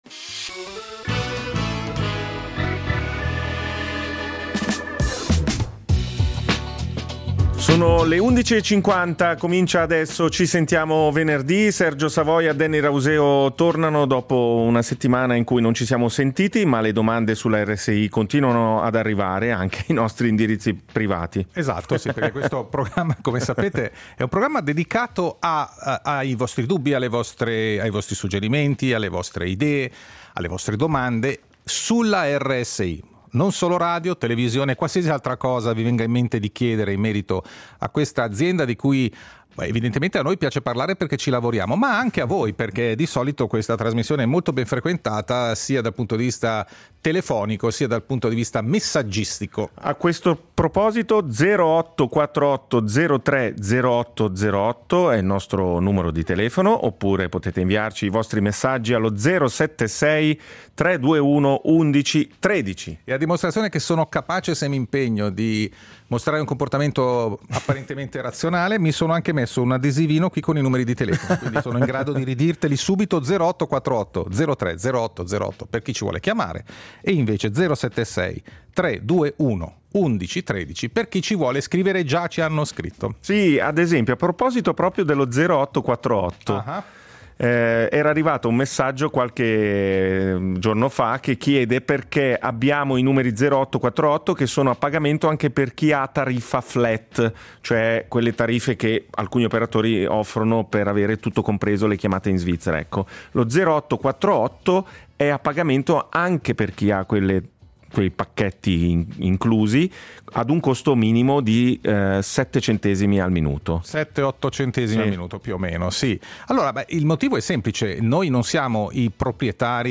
Insomma, “Ci sentiamo venerdì” è il tavolo radiofonico dove ci si parla e ci si ascolta.